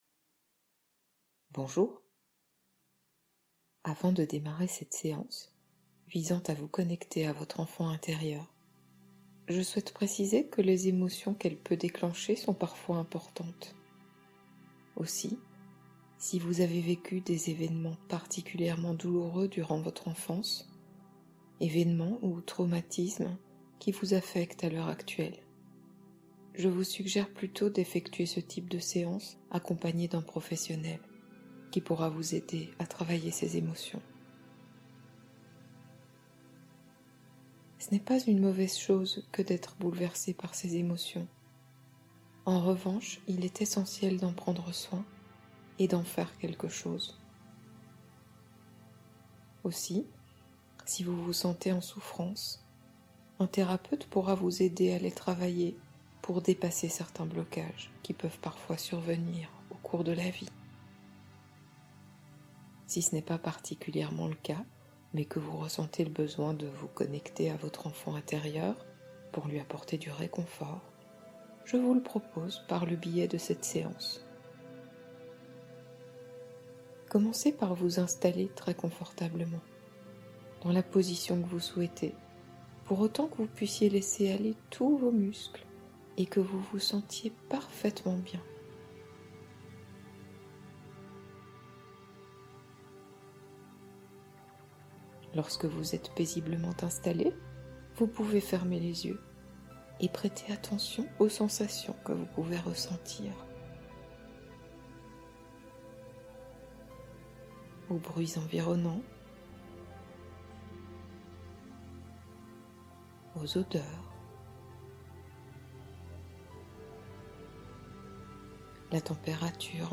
Rencontrez et guérissez votre enfant intérieur blessé (hypnose profonde)